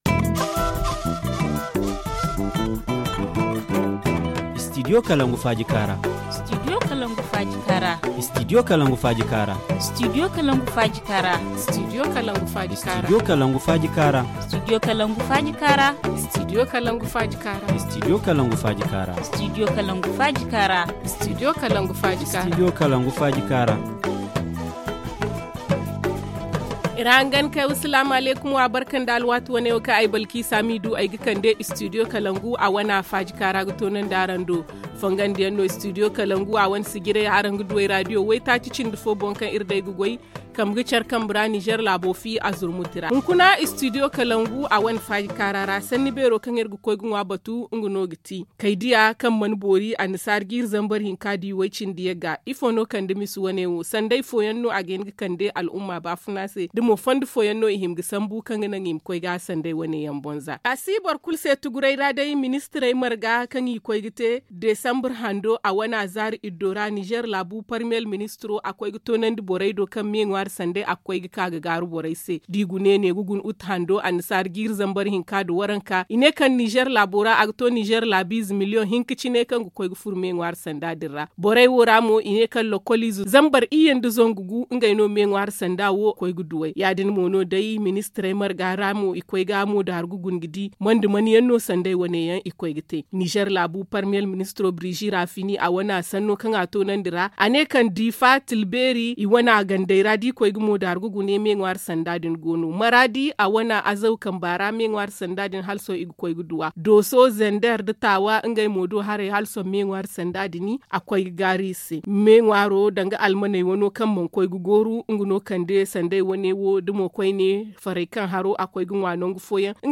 Au téléphone
Le forum en zarma